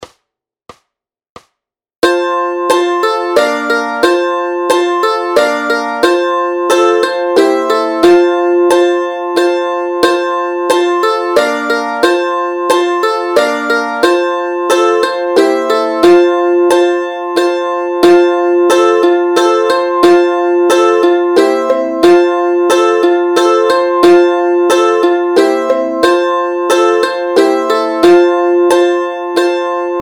Noty, tabulatury, akordy na mandolínu.
Hudební žánr Vánoční písně, koledy